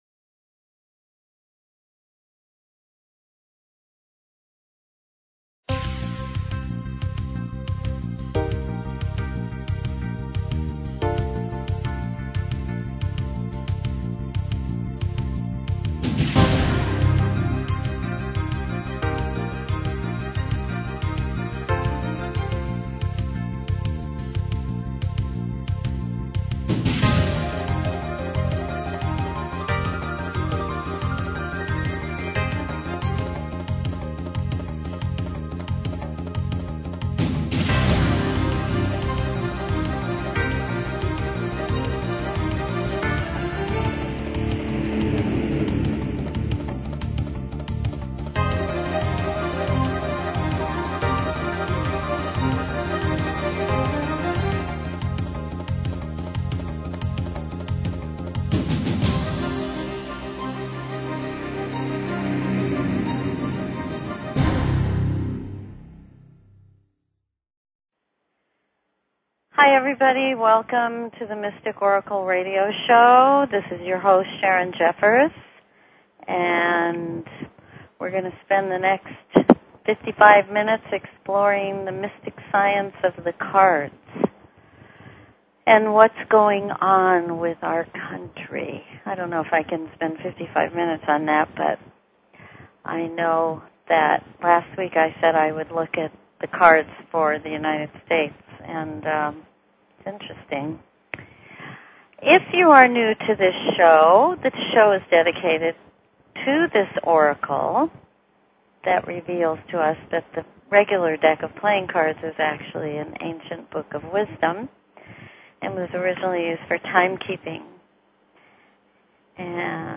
Talk Show Episode, Audio Podcast, The_Mystic_Oracle and Courtesy of BBS Radio on , show guests , about , categorized as
Discover the secrets hidden in your birthday, your relationship connections, and your life path. Open lines for calls.